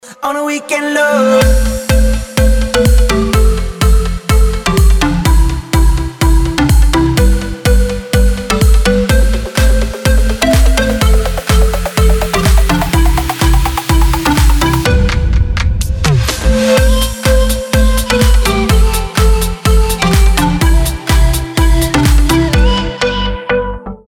• Качество: 320, Stereo
танцы